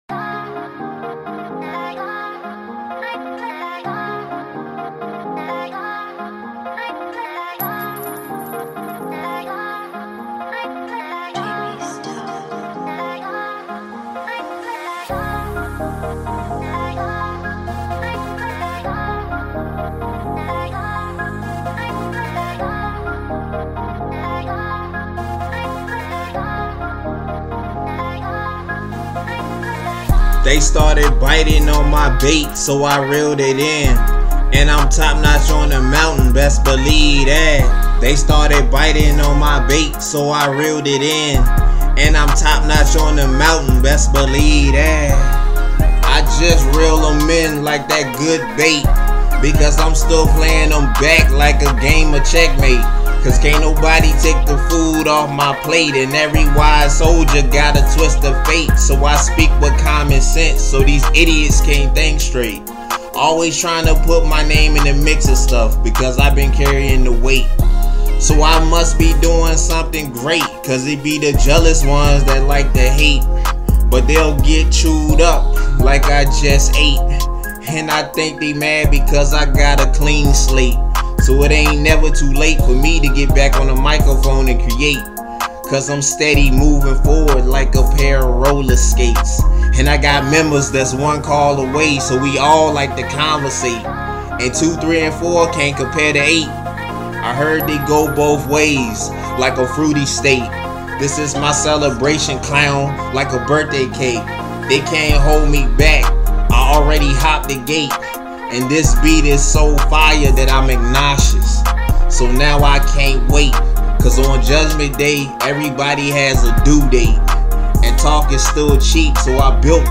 Rap
this song right here is definitely booming